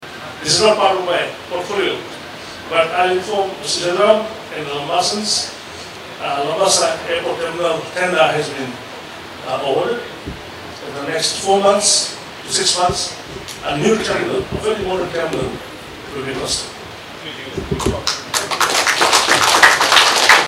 Singh announced the update during his address at the opening of BRED Bank’s Labasa Branch last Saturday, clarifying queries by individuals and businesses in the Northern Division.
Minister for Sugar and Multi-Ethnic Affairs, Charan Jeath Singh [Source: Parliament of the Republic of Fiji/Facebook]